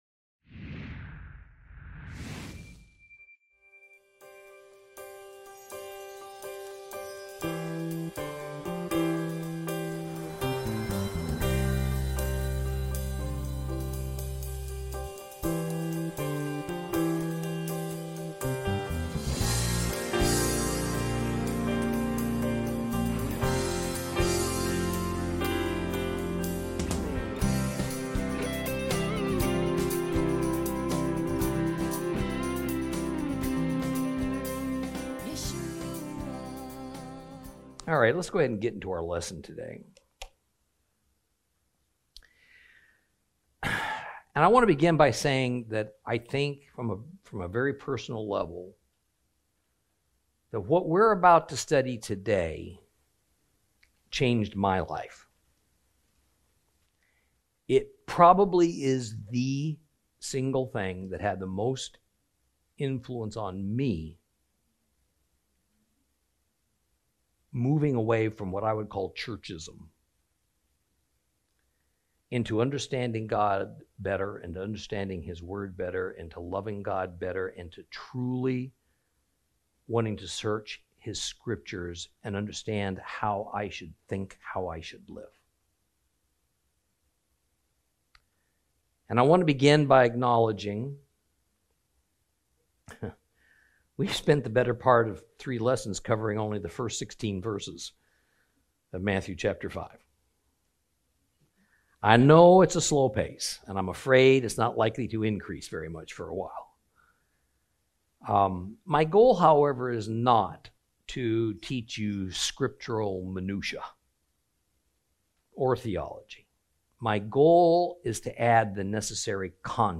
Lesson 15 Ch5 - Torah Class